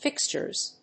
/ˈfɪkstʃɝz(米国英語), ˈfɪkstʃɜ:z(英国英語)/